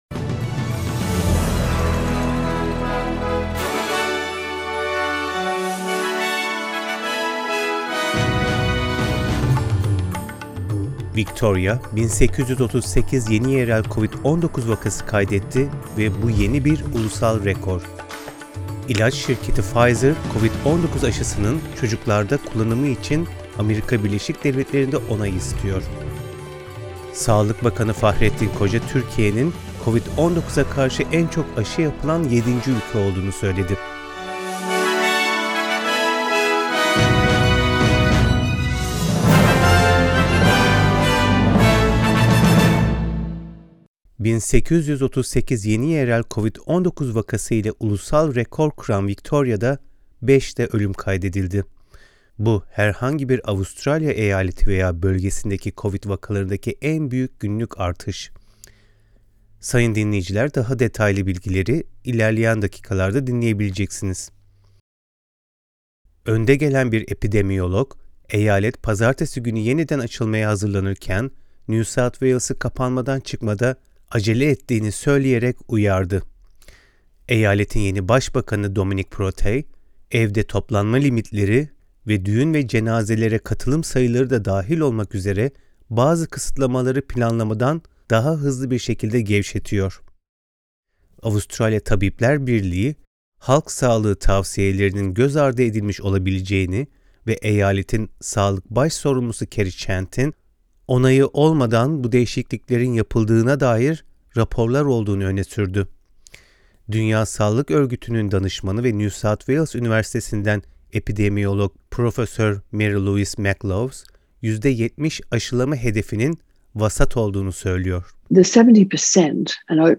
SBS Türkçe Haberler 8 Ekim